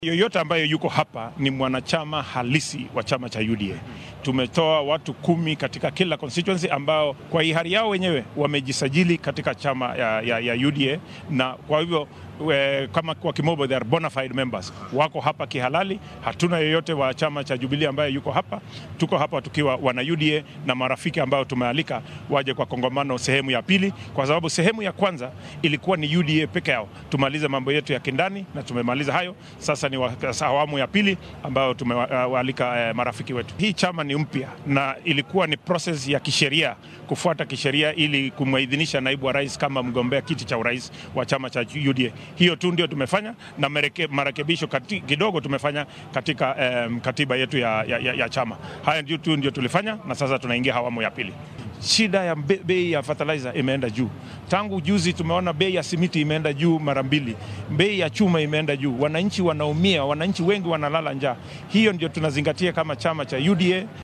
Xildhibaanka deegaanka Soi Caleb Kositany ayaa faahfaahin ka bixinaya siyaasiyiinta ka qayb galay shirweynaha maanta.